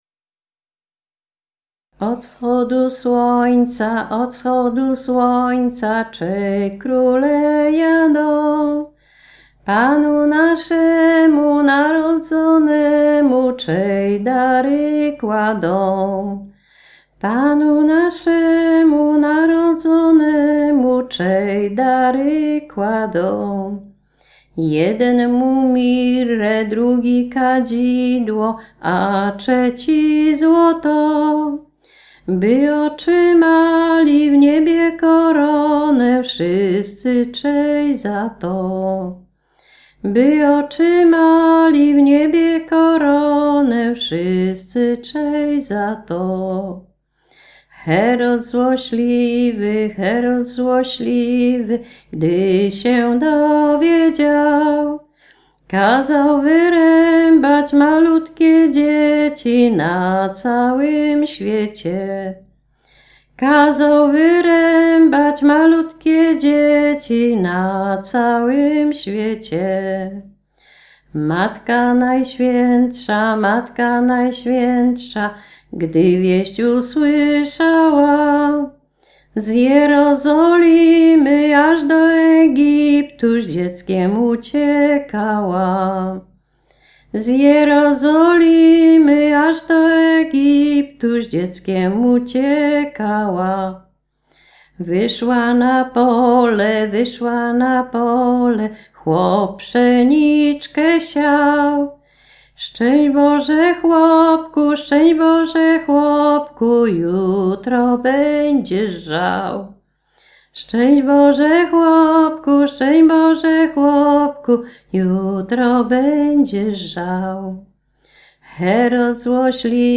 Pochodzenie: Tarnopolskie, powiat buczacki, wieś Podzameczek
Kolęda
kolędy zimowe